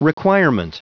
Prononciation du mot : requirement